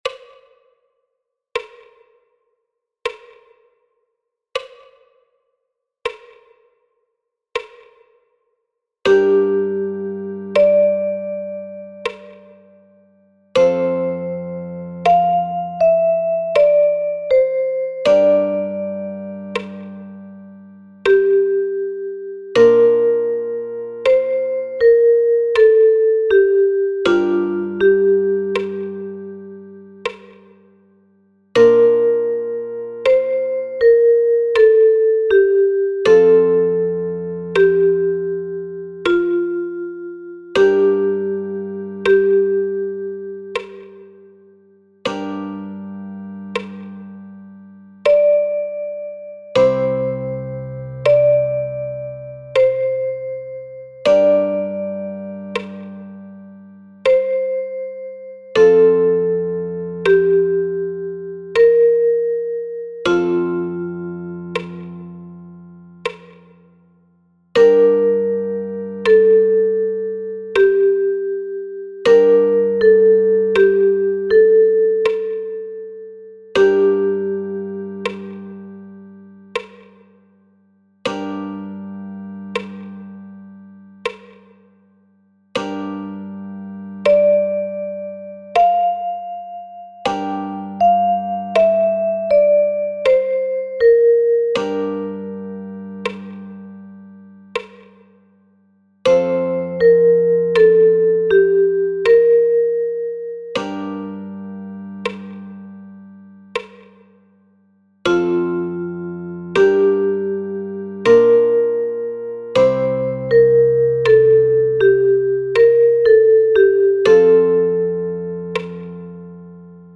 Für Altblockflöte in F.